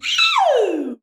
IIIIIIUUU.wav